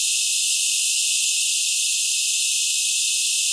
rr3-assets/files/.depot/audio/sfx/forced_induction/f1_spool2.wav